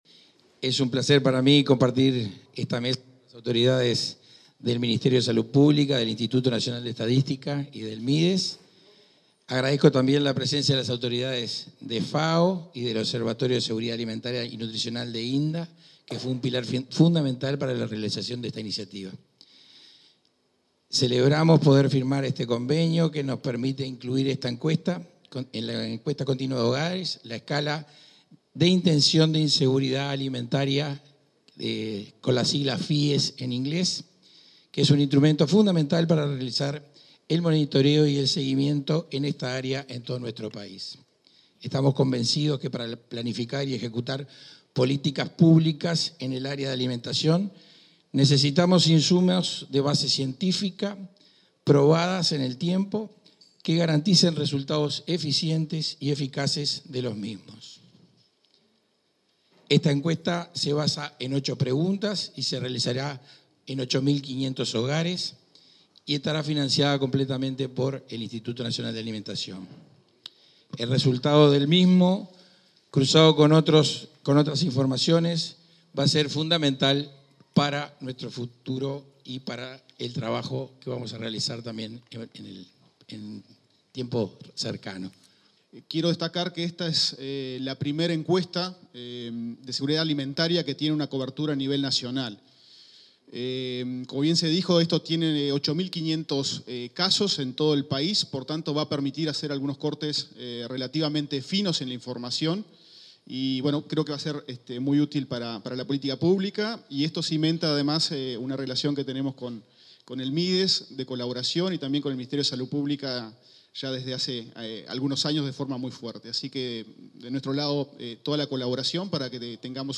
Palabras de autoridades en firma de convenio Mides, MSP e INE